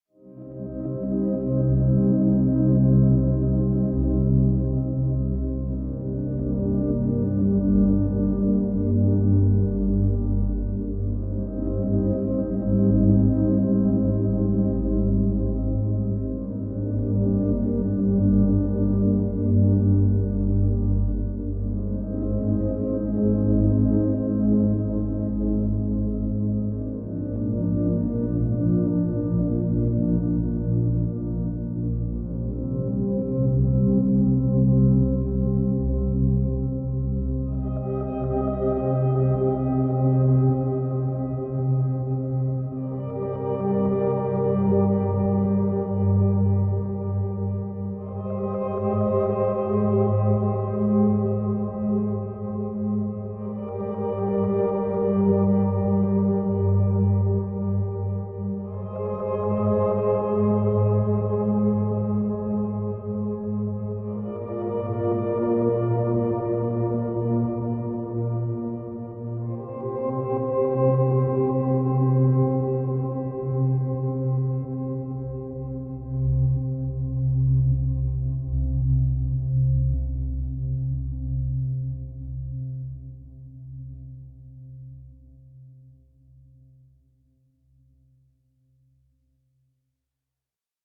nostalgic dreamy